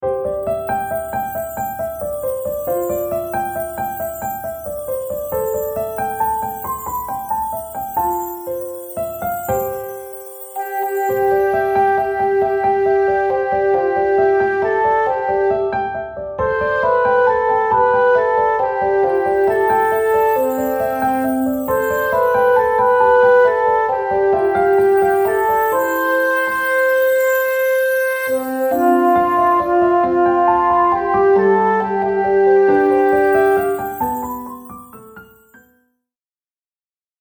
This is the unison version.
Genre : Australian lyrical song.
Suitable for : primary to lower secondary choirs.